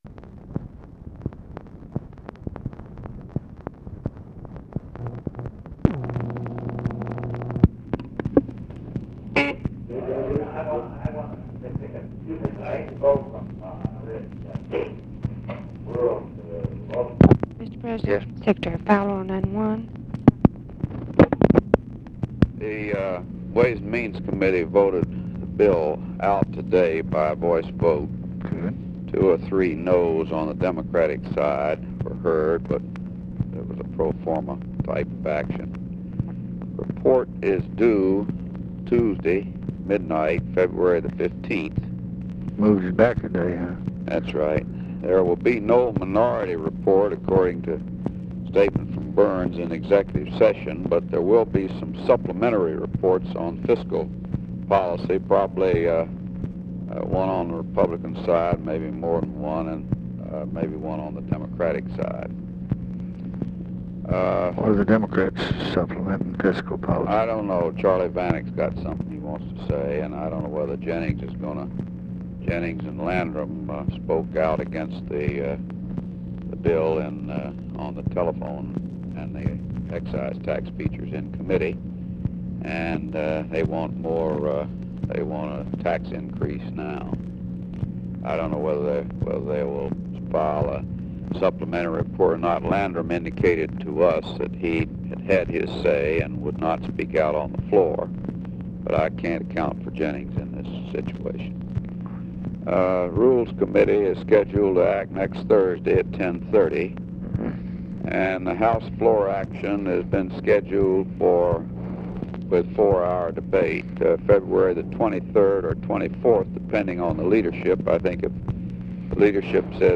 Conversation with HENRY FOWLER and OFFICE CONVERSATION, February 10, 1966
Secret White House Tapes